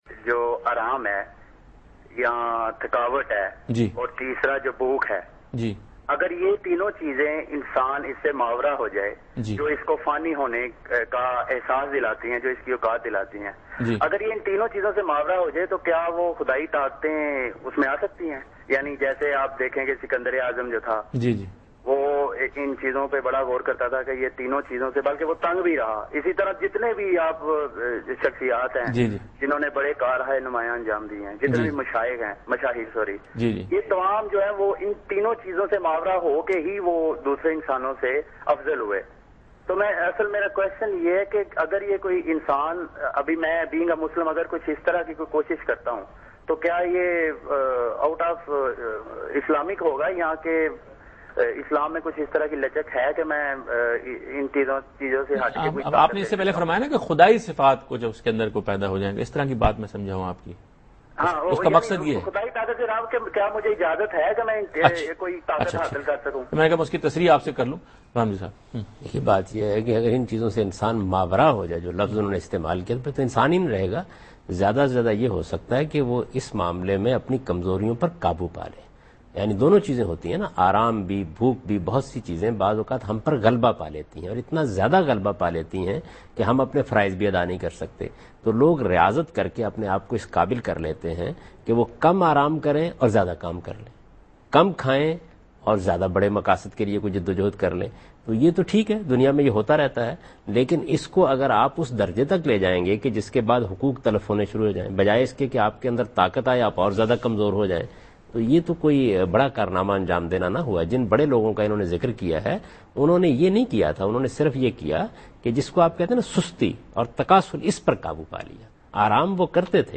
Category: TV Programs / Dunya News / Deen-o-Daanish /
Javed Ahmad Ghamidi answers a question about "Godly Powers" in program Deen o Daanish on Dunya News.
جاوید احمد غامدی دنیا نیوز کے پروگرام دین و دانش میں خدائی طاقتوں سے متعلق ایک سوال کا جواب دے رہے ہیں۔